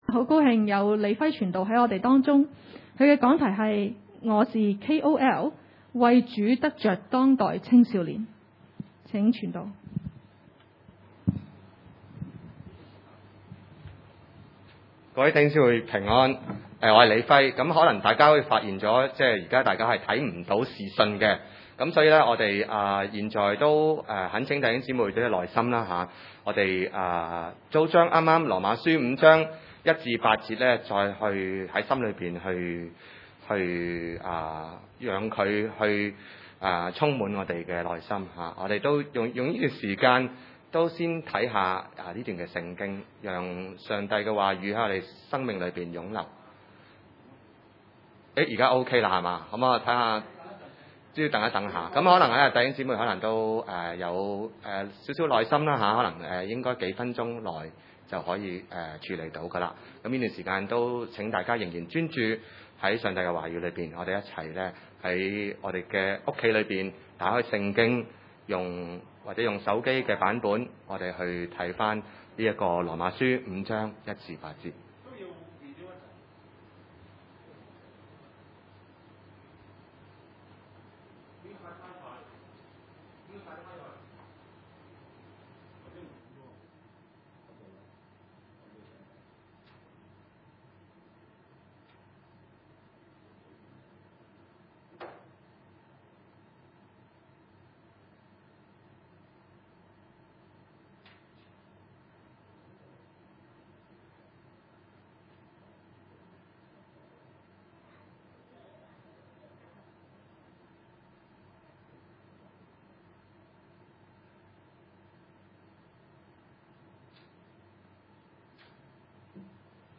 羅馬書5:1-8 崇拜類別: 主日午堂崇拜 1 我們既因信稱義，就藉着我們的主耶穌基督得與神相和。